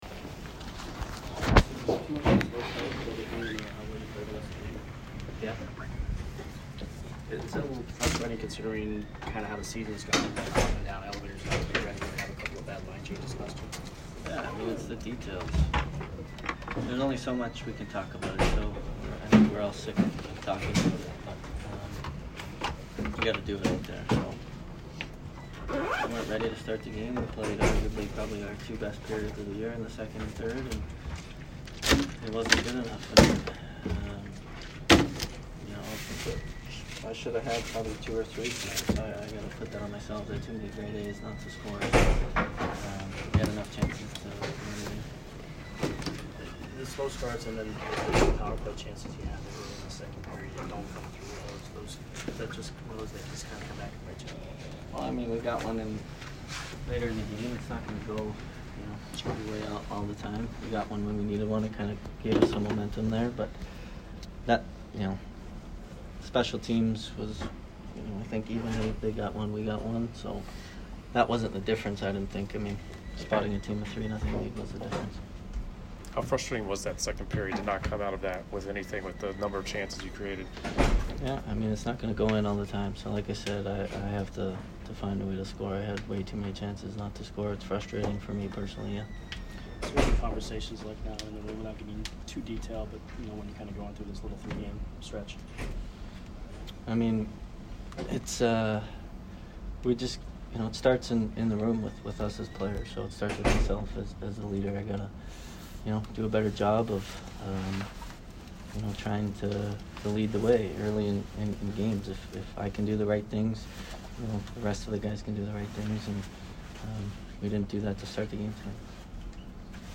Stamkos post-game 11/30